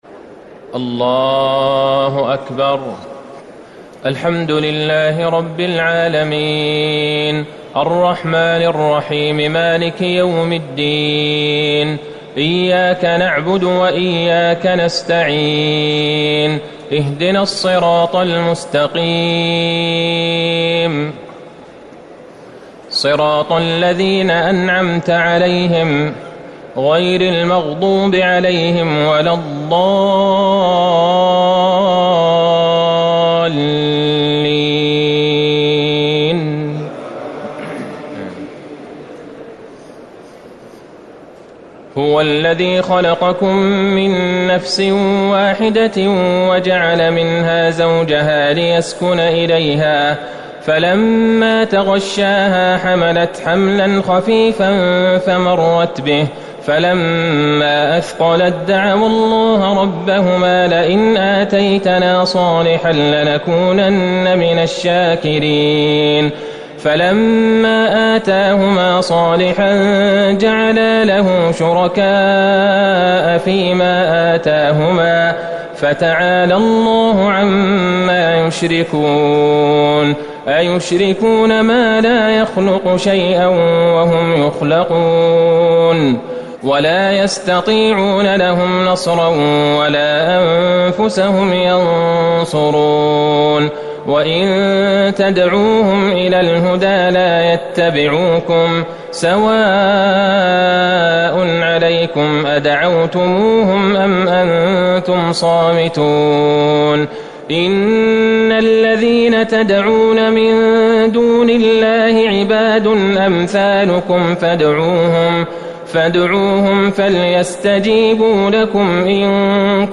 تهجد ٢٩ رمضان ١٤٤٠ من سورة الأعراف ١٨٩ - الأنفال ٤٠ > تراويح الحرم النبوي عام 1440 🕌 > التراويح - تلاوات الحرمين